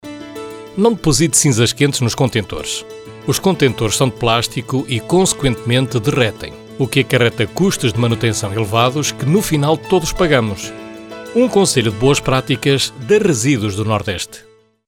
Spot cinzas quentes –